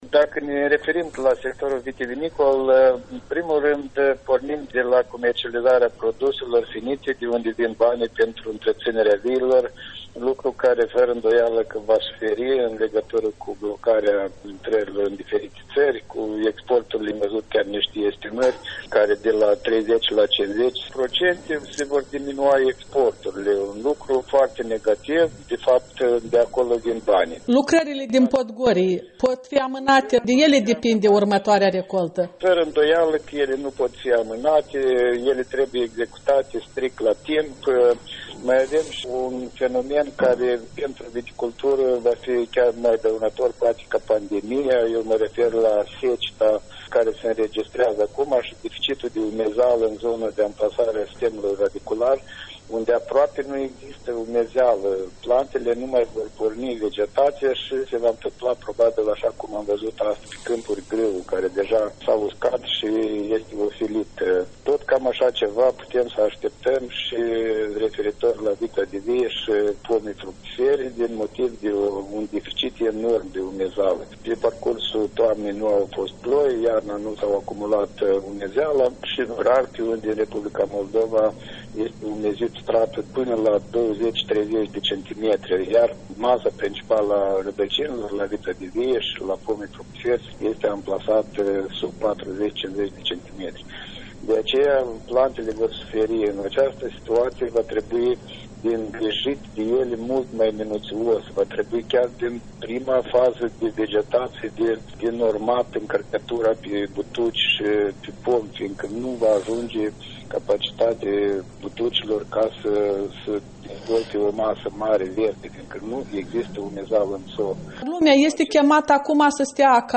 Interviu cu fostul șef al Agenției Naționale pentru Siguranța Alimentelor despre efectele secetei și ale pandemiei.
Interviu cu Gheorghe Gaberi